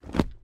Звуки падения
Звук приземления на ноги, похожий на мягкую посадку